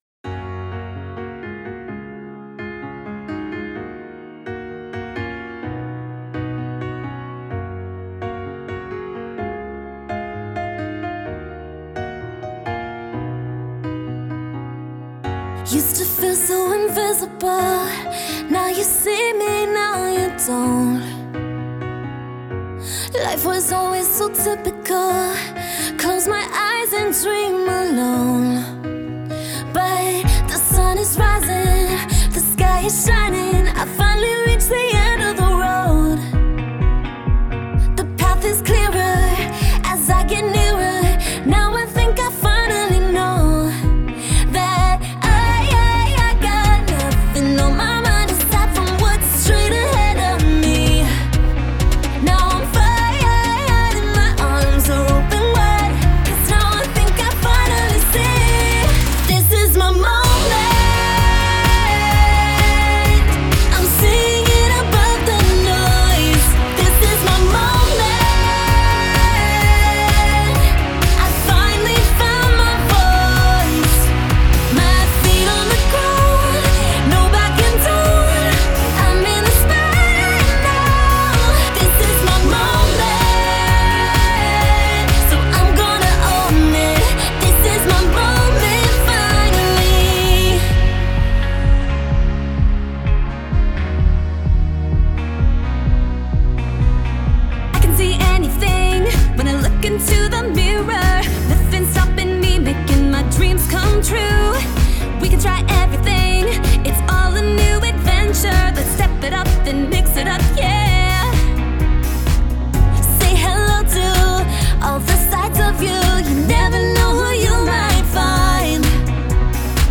Жанр: Pop music
Genre - Pop, Aqua-core